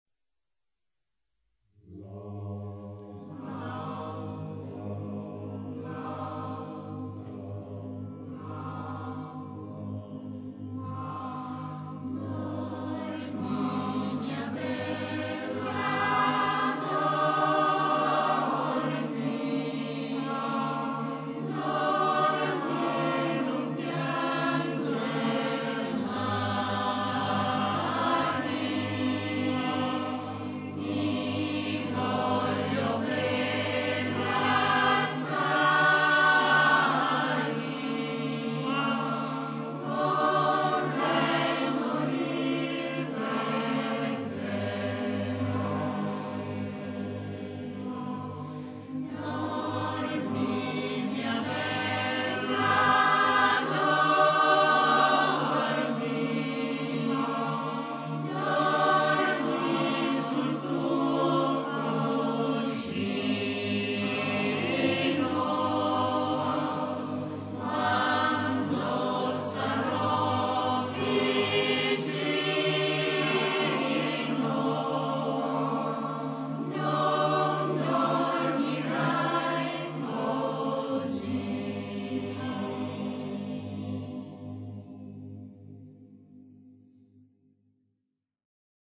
[ voci miste ]